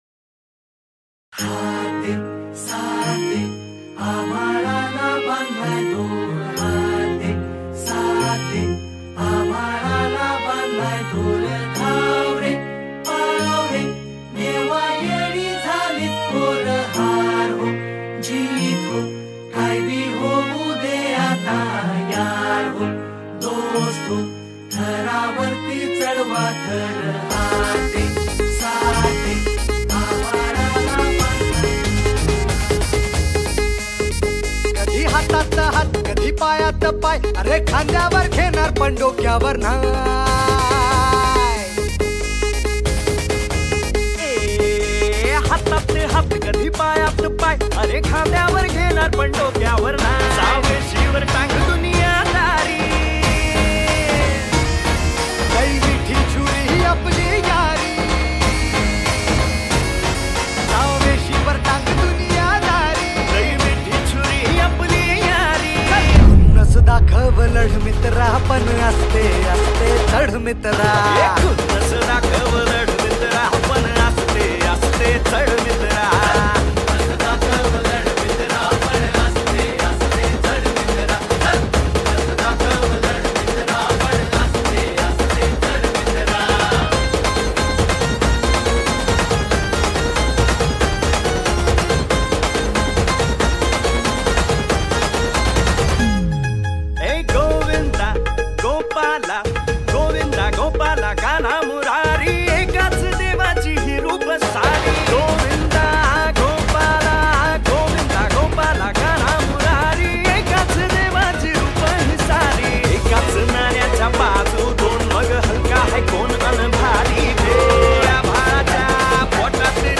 Categories Indian Festival Ringtones